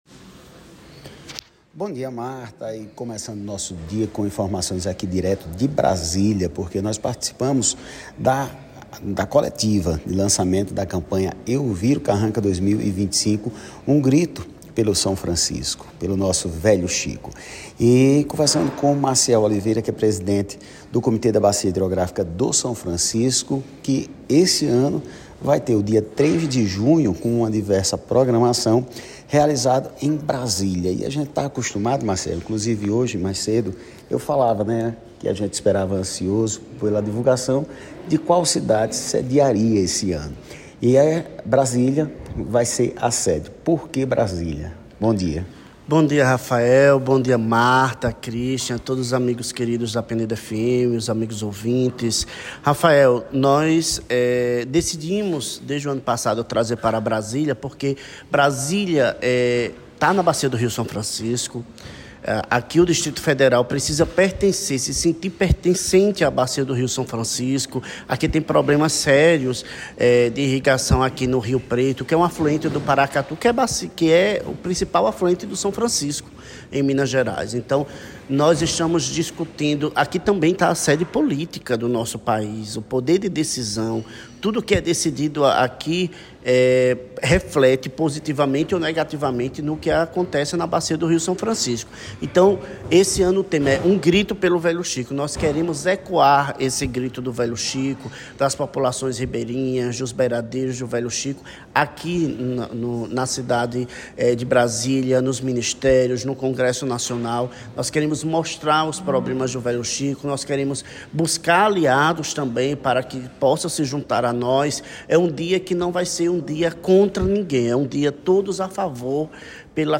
via contato telefônico